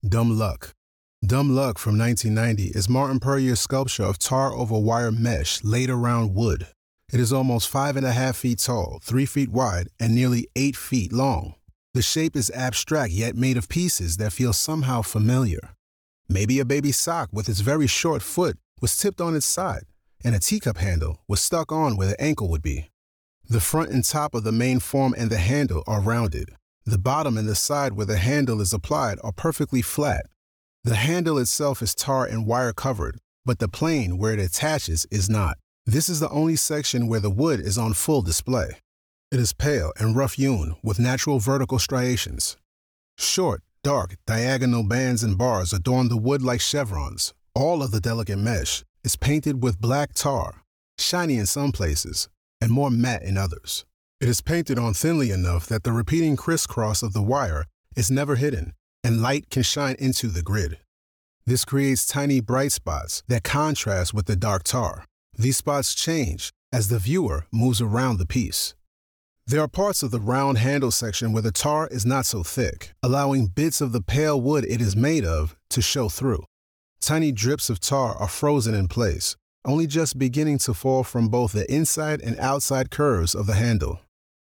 Audio Description (01:25)